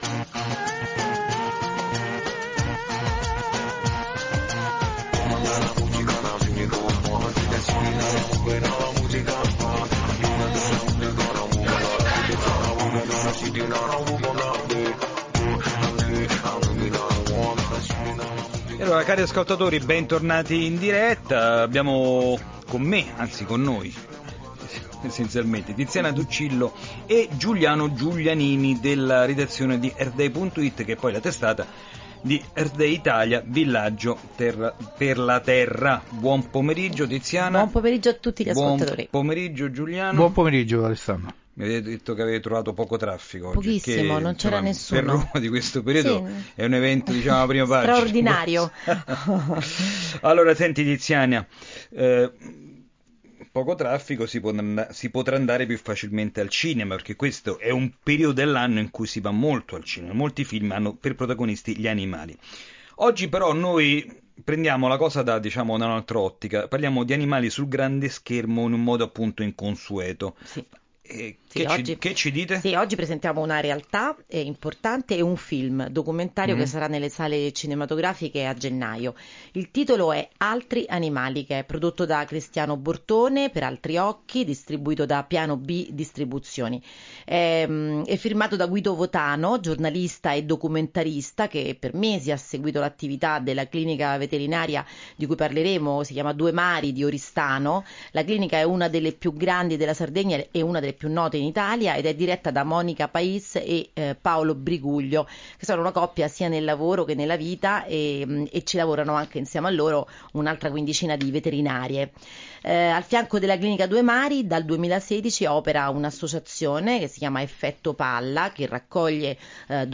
La puntata odierna di “Ecosistema”, la rubrica radiofonica settimanale di Earth Day Italia trasmessa da Radio Vaticana, ha raccontato in anteprima un film-documentario che sarà nelle sale cinematografiche dal 21 gennaio: “Altri animali”, prodotto da Cristiano Bortone per “Altri occhi”, distribuito da “Piano B Distribuzioni”.